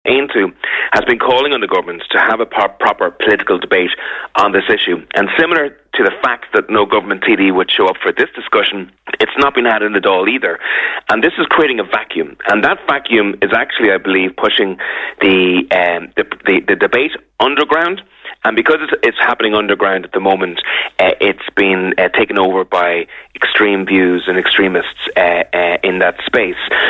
Speaking on Kildare today, Aontú Leader Peadar Tóibín emphasised the need to avoid making Ireland an appealing destination for migrants solely based on benefit rates.